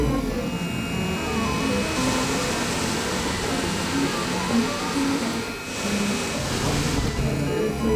loop_3d_printer.flac